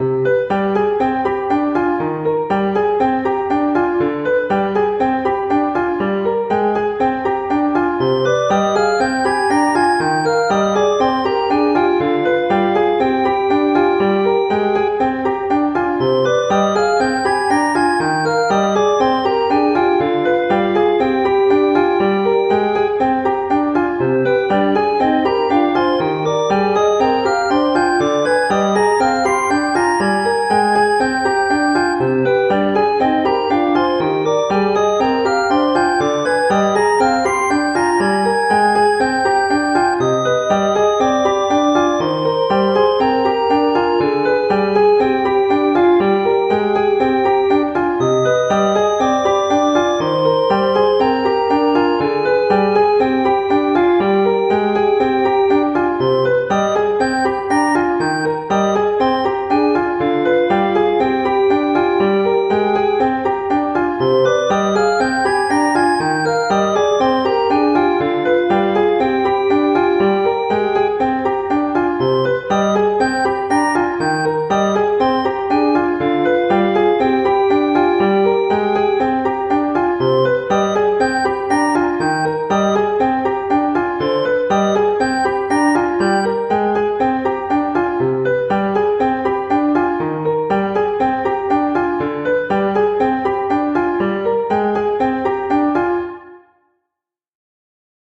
Dúo para piano y órgano
piano
dúo
melodía
órgano